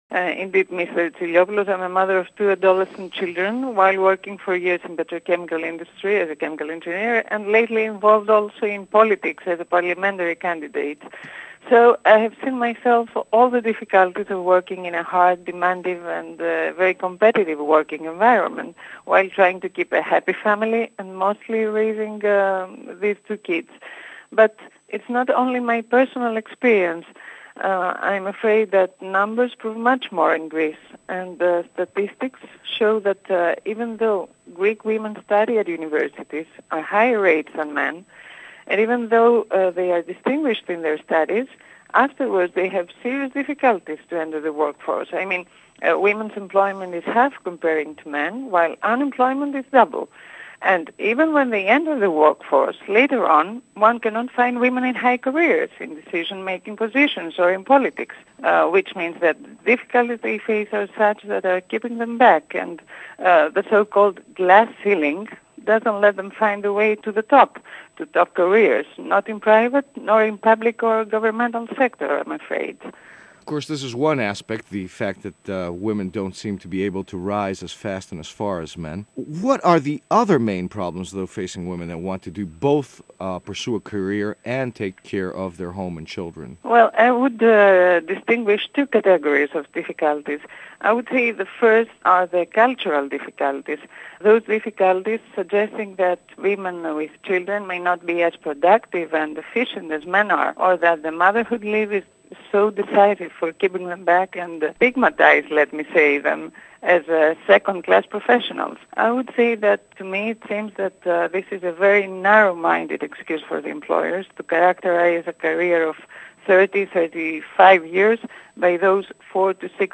Οικογένεια και Καριέρα – Radio Interview